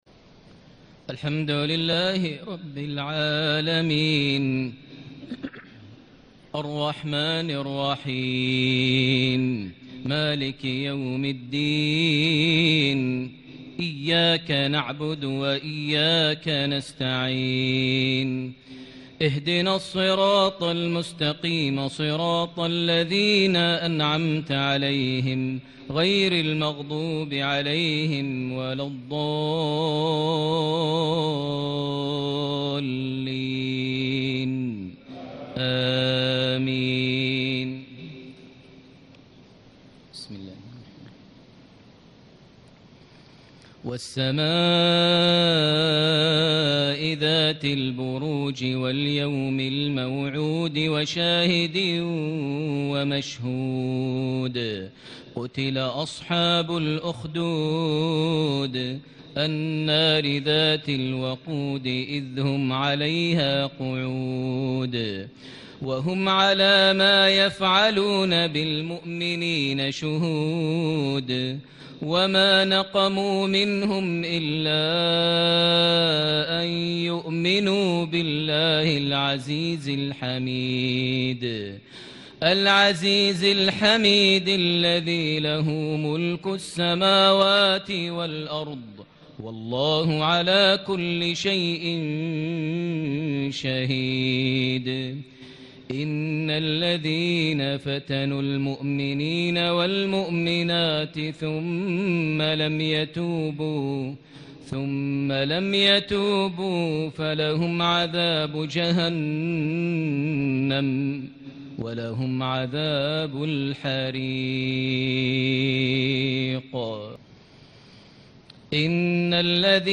صلاة المغرب ٢١ ذي الحجة ١٤٣٩هـ سورة البروج > 1439 هـ > الفروض - تلاوات ماهر المعيقلي